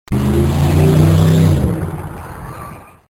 Jeep drive away
Category: Sound FX   Right: Personal